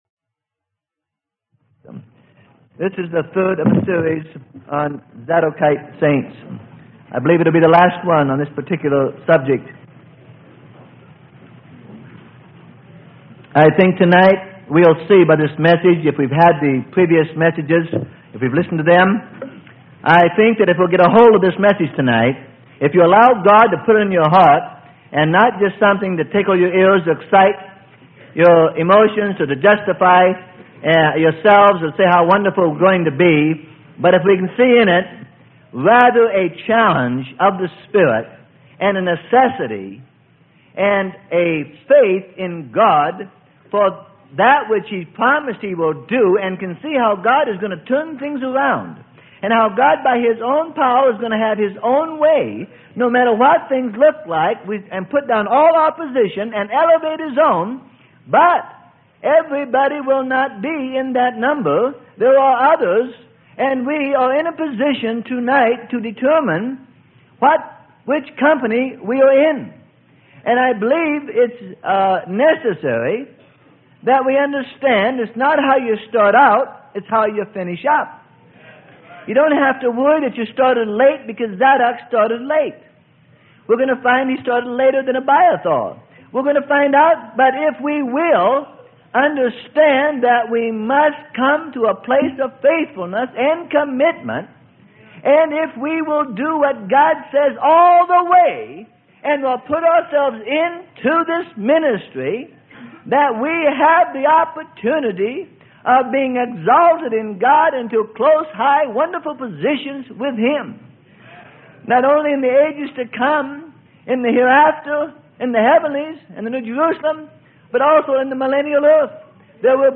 Sermon: Becoming Zadokite Priests - Part 3 - Freely Given Online Library